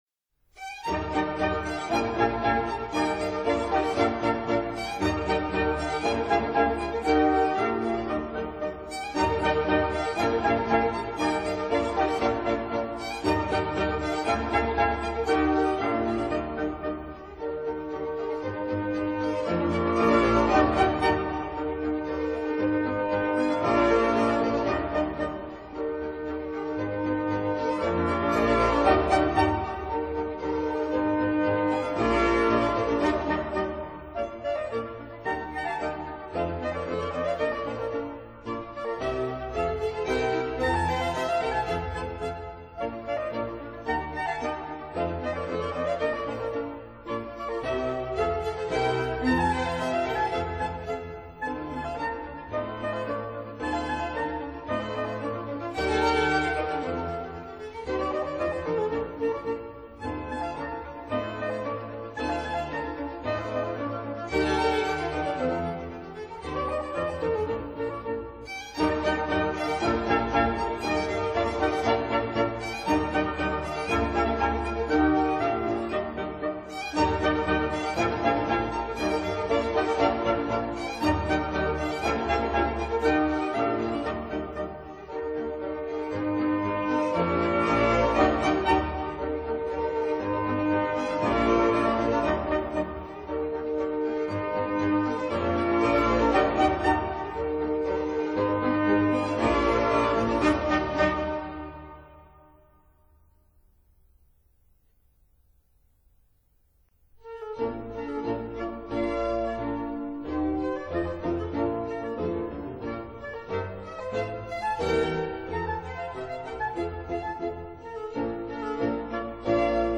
Violin concerto in D major- Allegro non troppo